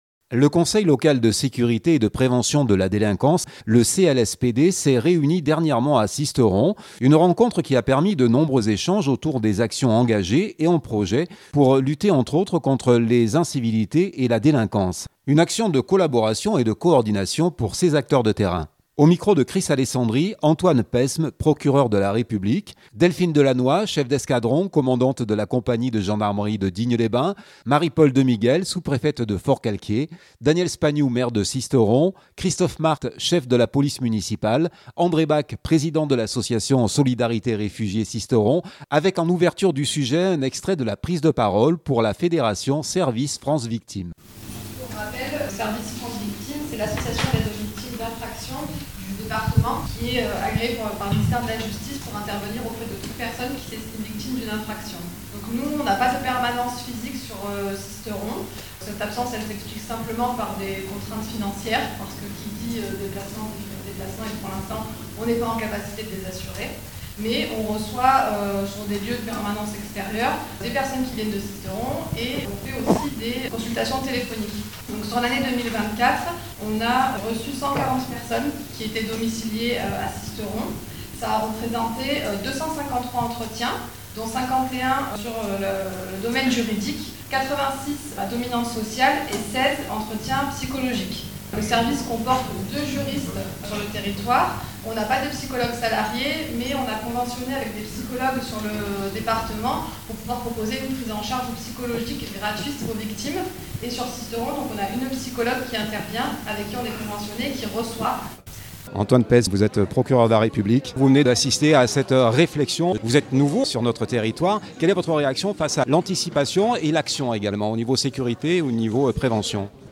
Avec en ouverture du sujet un extrait de la prise de parole pour la fédération:Service France Victime